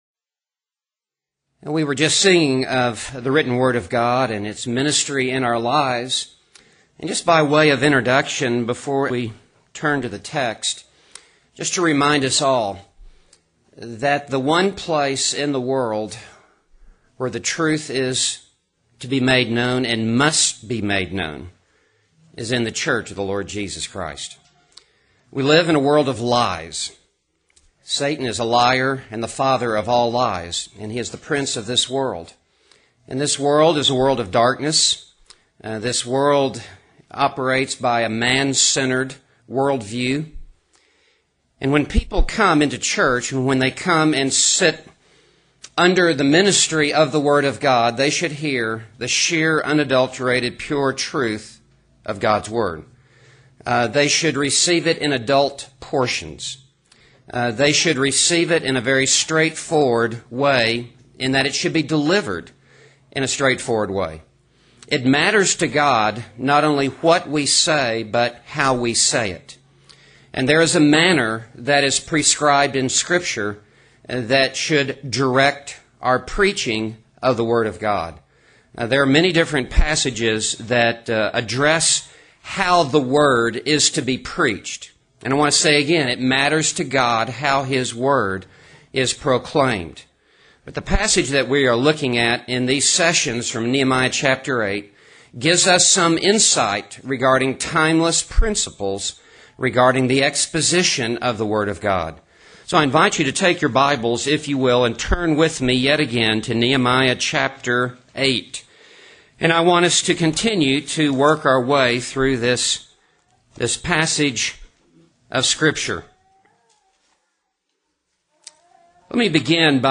09 Q&A